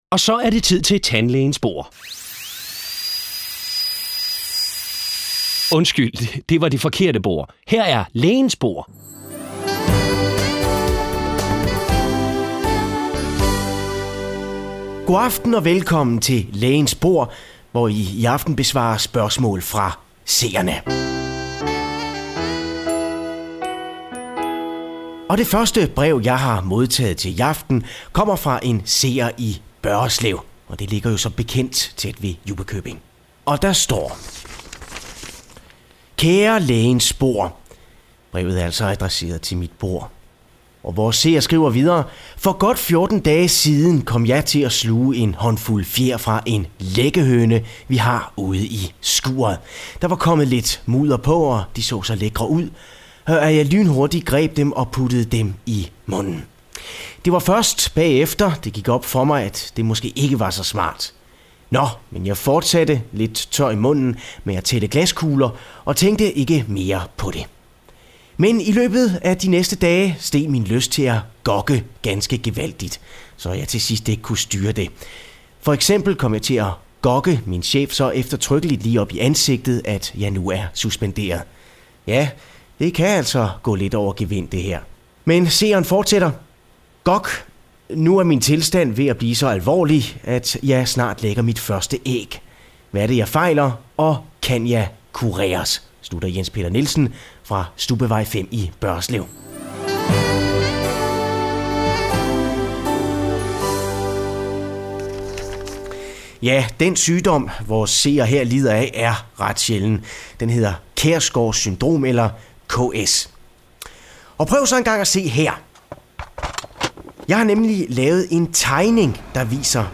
Det er her, du kan høre alle de gode, gamle indslag fra ANR's legendariske satireprogram.
For anden gang i Farlig Fredags historie blev redaktionen samlet til 3 timers "Farligt Nytår" nytårsaftensdag.